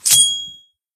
bp_snout_coin_03.ogg